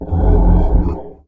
MONSTERS_CREATURES
MONSTER_Groan_03_mono.wav